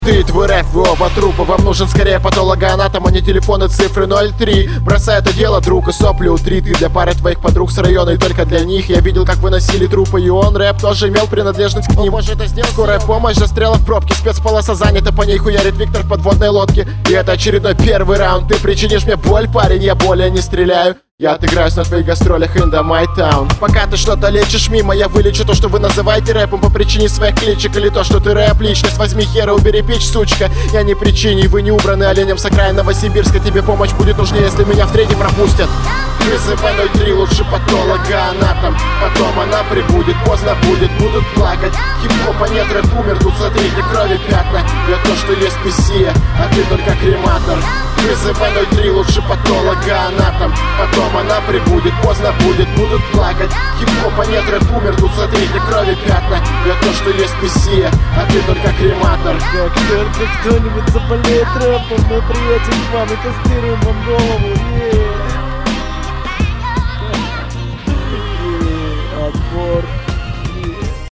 Рэп